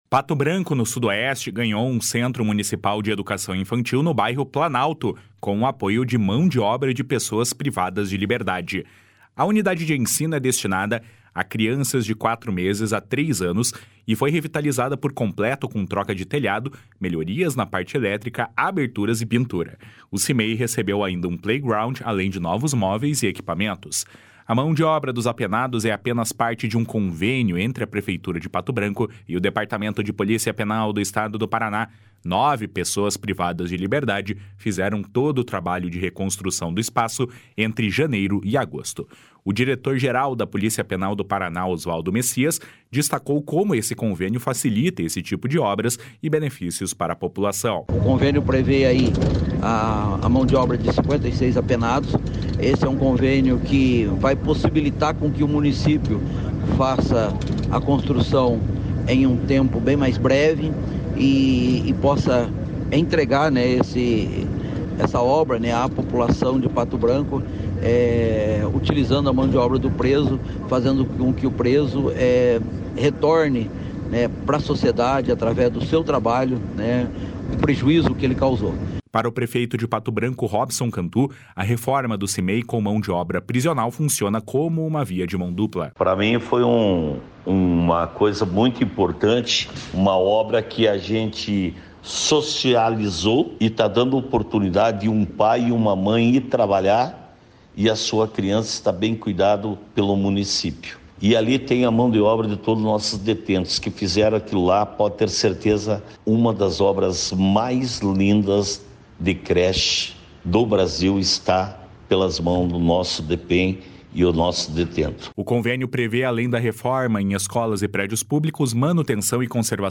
O diretor-geral da Polícia Penal do Paraná, Osvaldo Messias, destacou como o convênio facilita esse tipo de obras e benefícios para a população. // SONORA OSVALDO MESSIAS //
Para o prefeito de Pato Branco, Robson Cantu, a reforma do CMEI com mão de obra prisional funciona como uma via de mão dupla. // SONORA ROBSON CANTU //